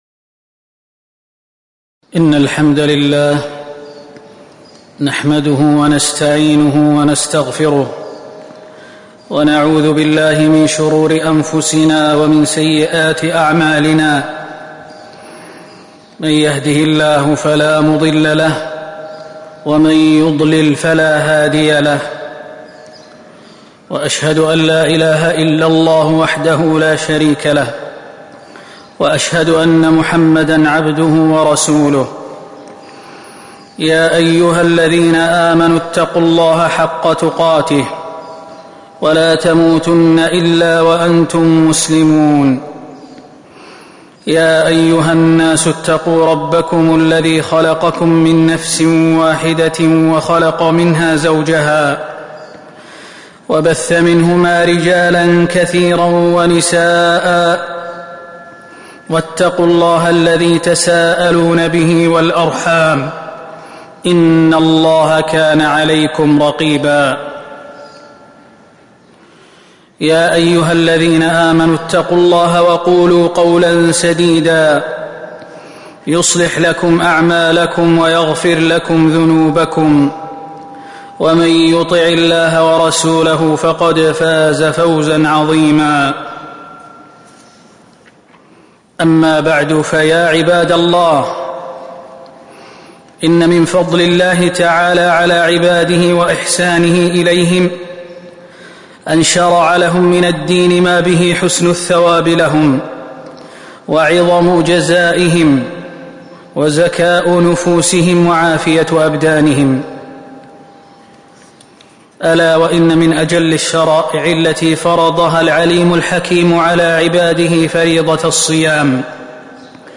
تاريخ النشر ٢٥ شعبان ١٤٤٤ هـ المكان: المسجد النبوي الشيخ: فضيلة الشيخ د. خالد بن سليمان المهنا فضيلة الشيخ د. خالد بن سليمان المهنا الصيام أحكامه وفضائله